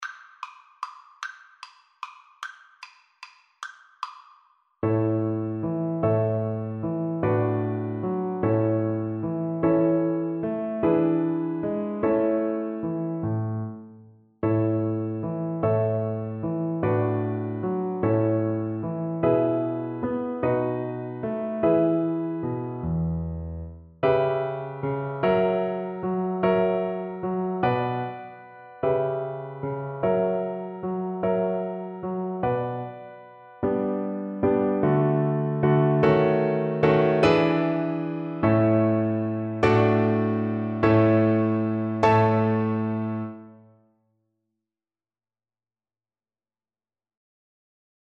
Cello
3/8 (View more 3/8 Music)
A major (Sounding Pitch) (View more A major Music for Cello )
Andante .=c.50
Easy Level: Recommended for Beginners with some playing experience
Classical (View more Classical Cello Music)